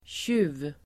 Ladda ner uttalet
Uttal: [tju:v]